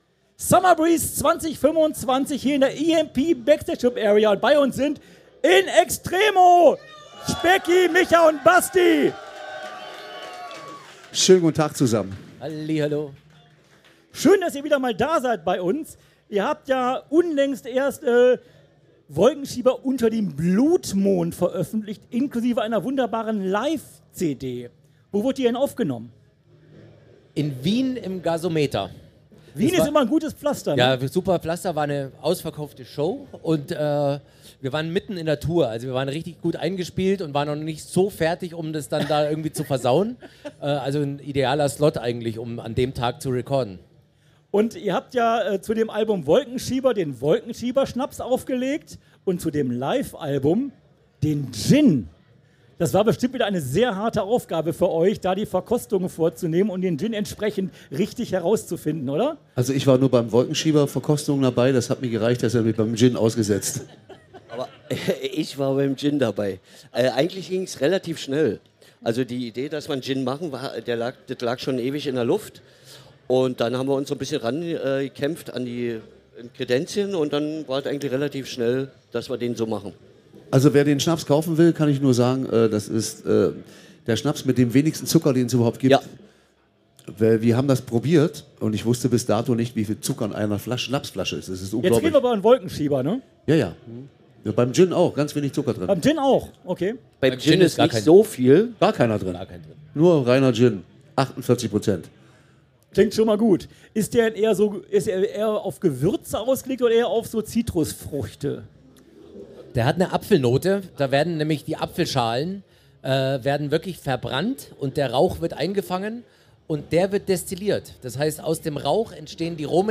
Summer Breeze 2025 Special - In Extremo - Live aus der EMP Backstage Club Area